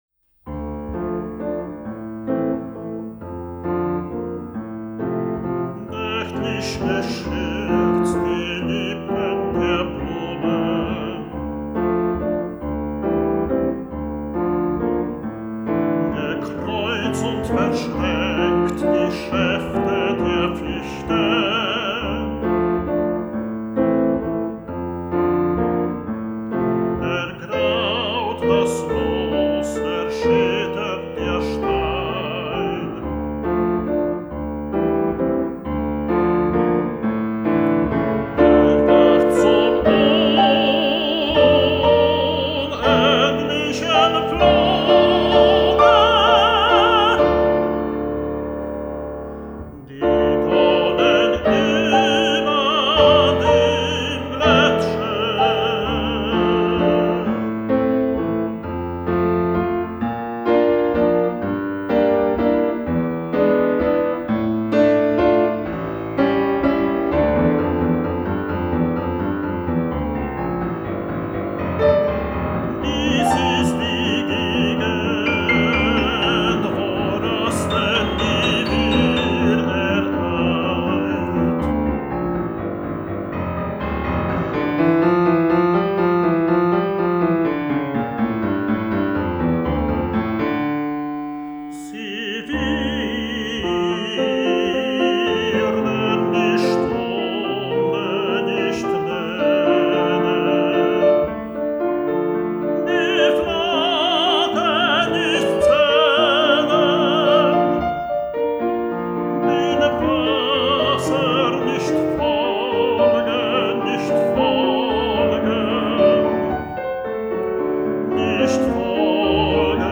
tenor
fortepian
sonoryzm, onomatopeja muzyczna